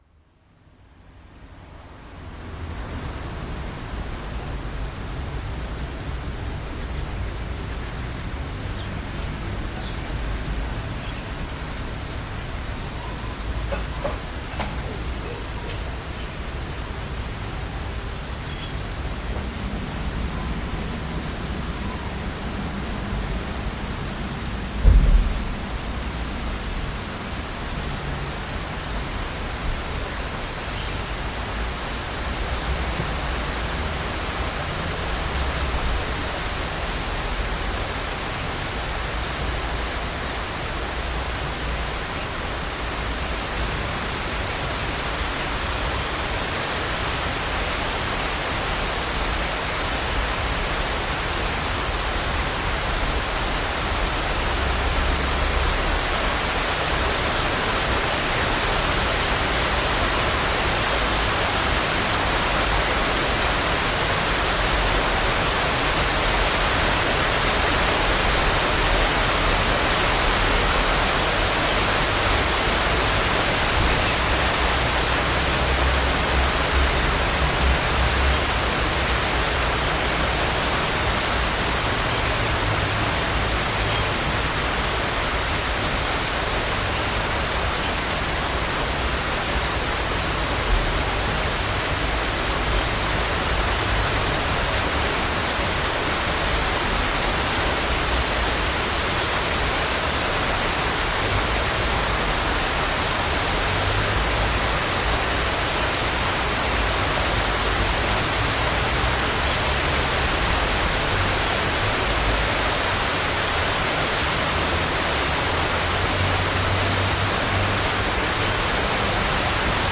整个周日下午都在下雨。有几次雨势加大，并在一段时间内变得严重。这就是我的记录。